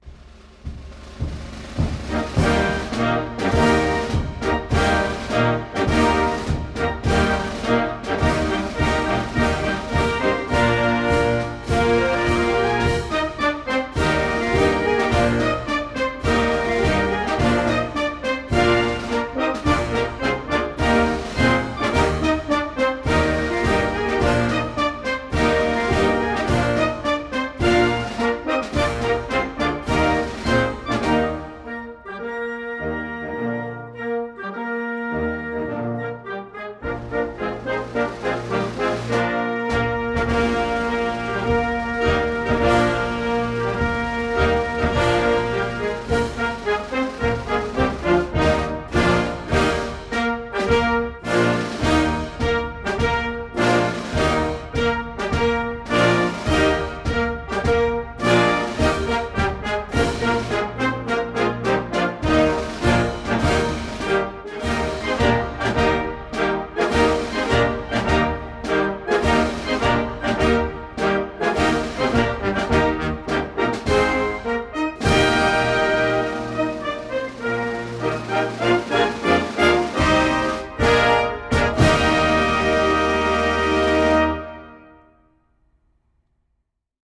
hymne_national_debut_congolais.mp3